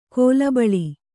♪ kōlabaḷi